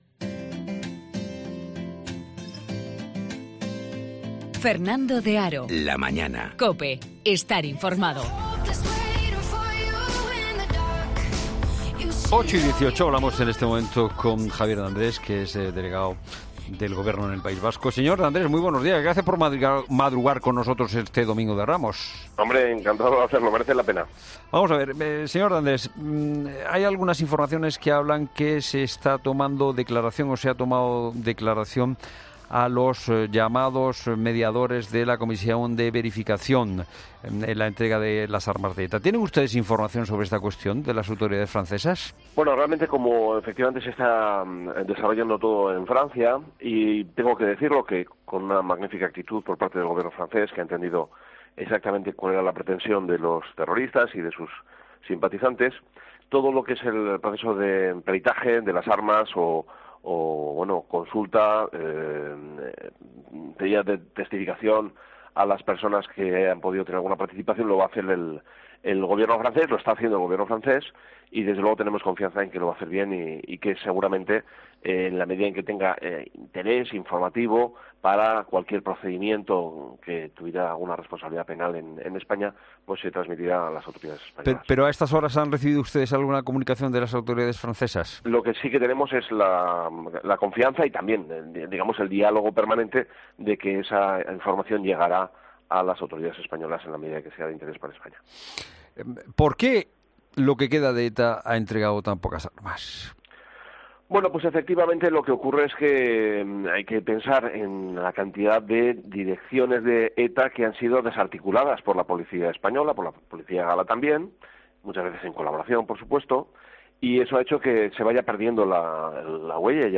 Entrevista política